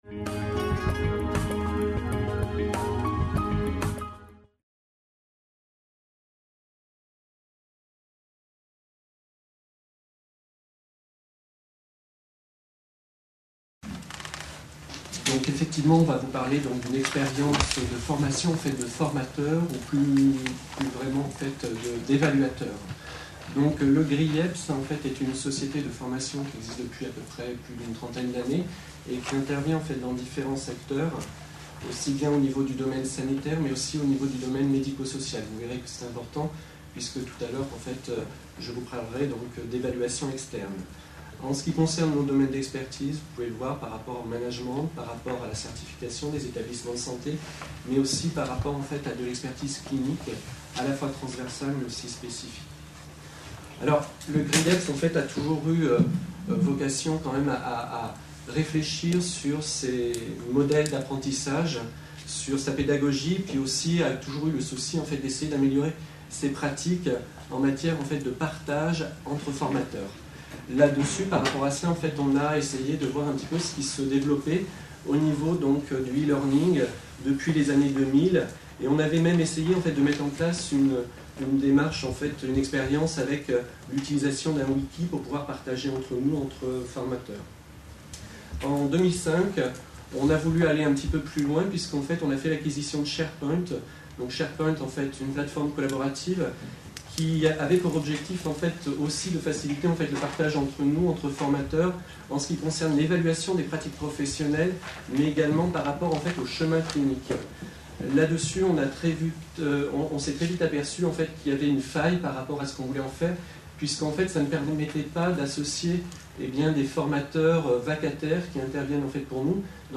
Conférence enregistrée lors du congrès international FORMATIC PARIS 2011.